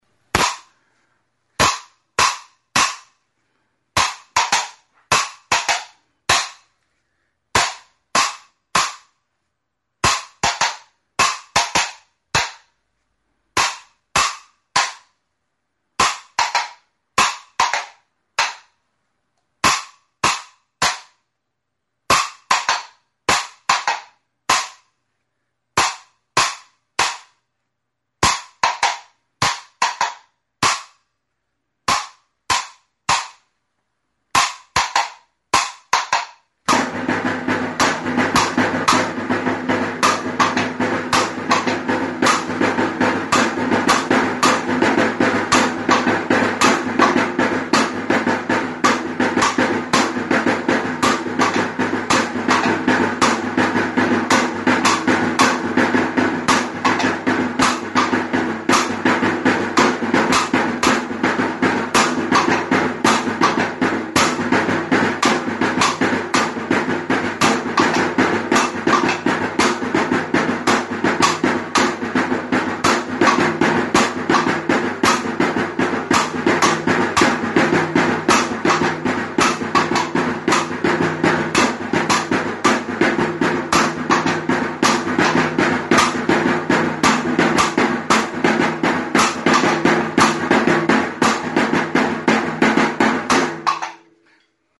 Idiophones -> Struck -> Directly
Recorded with this music instrument.
PORRAS; PITOS
Bi osagaiez osatutako soinu-tresna da: kirtena duten zurezko kaskainetak dira, eta hauen kontra kolpatzeko maza bat.
WOOD; HOLM OAK